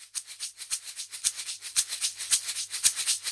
Sand Shaker
描述：Recorded with Contact Microphone on an Zoom H4n.
标签： Shake music Sand instrument
声道立体声